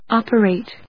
音節op・er・ate 発音記号・読み方
/άpərèɪt(米国英語), ˈɔpərèɪt(英国英語)/